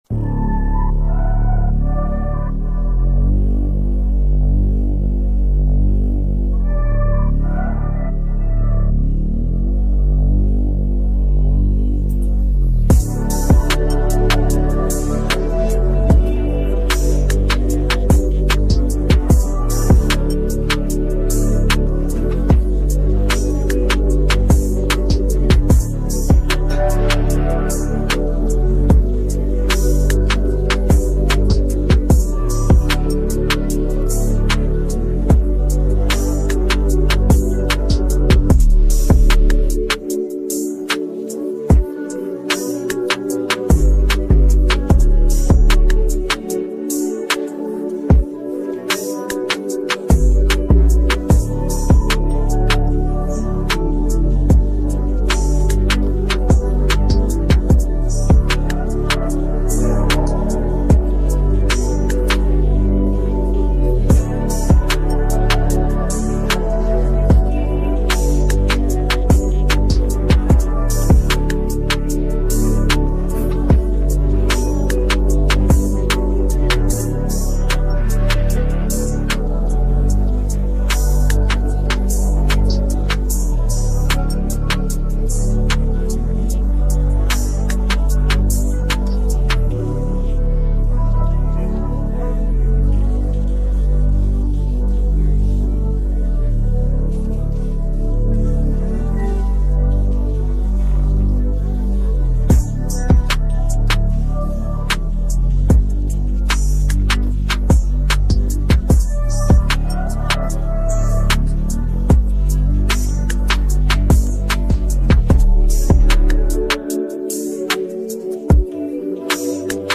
energetic beat and captivating instrumental melodies